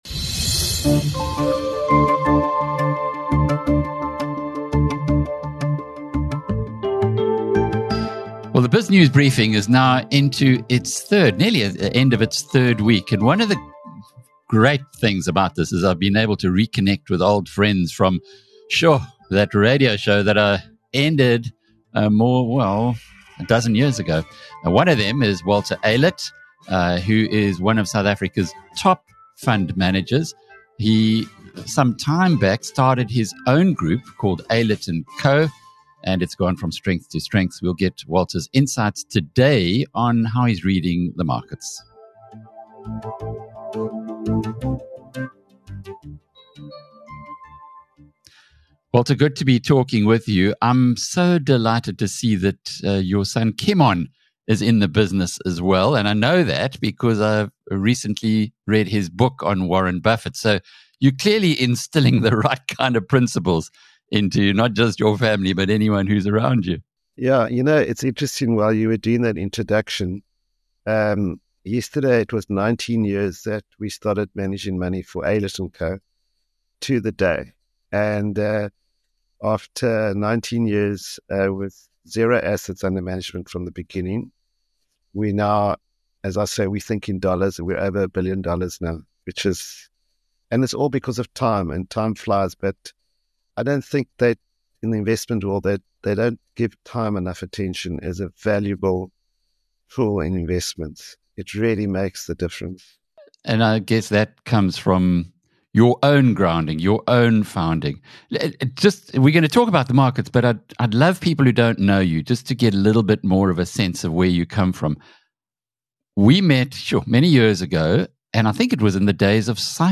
In an insightful interview